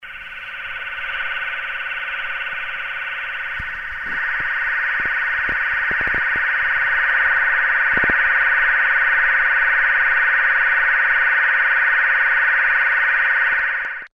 interference on 433.575 MHz. Found 2 construction cranes to be the source. Reported to MCA and interference stopped.